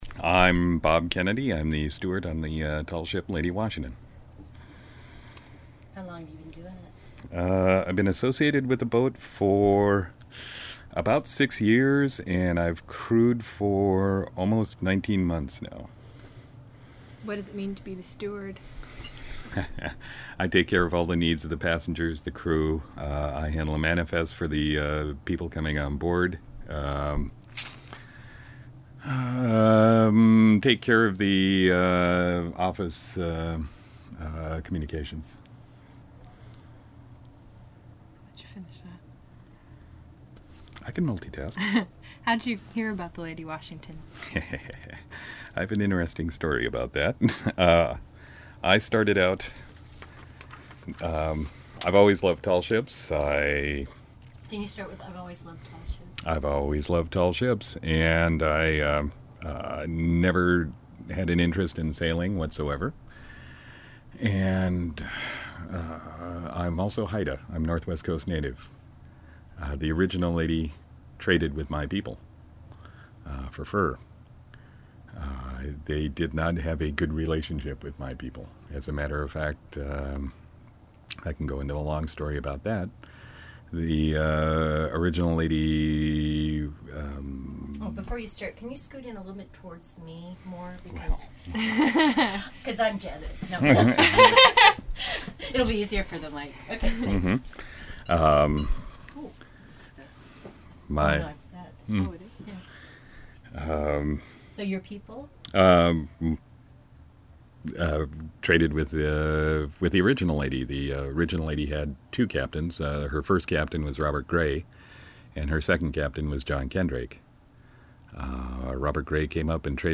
DOOR CLOSES W/ LATCH COMES BACK DOWN STAIRS LANTERN MAKES NOISE Read more...